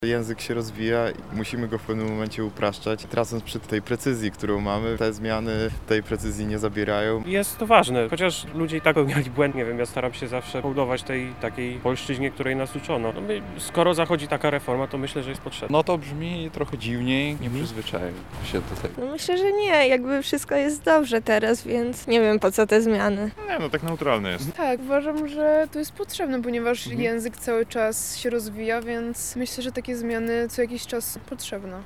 Zapytaliśmy mieszkańców Lublina, co sądzą o tym pomyśle:
Sonda
Sonda-ortografia-full.mp3